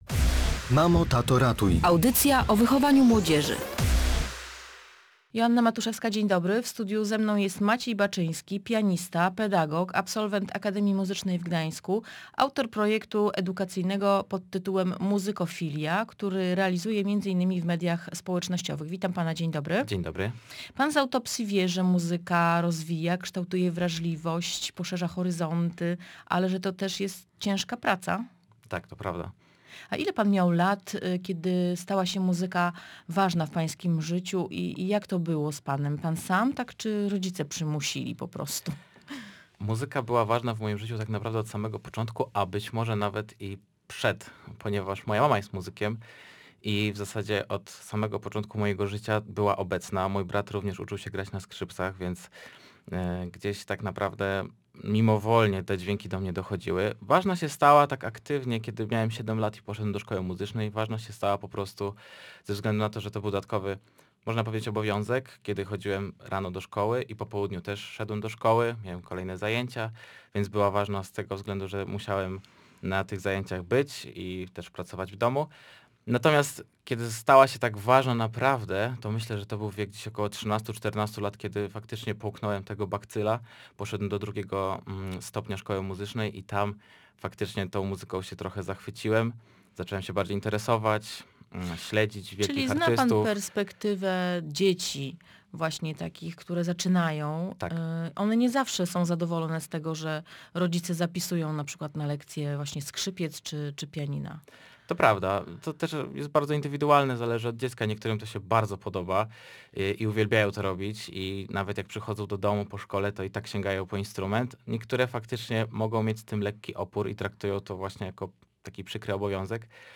Rozmowa dotyczyła rozwijania wrażliwości muzycznej dzieci i wpływu muzyki na budowanie osobowości.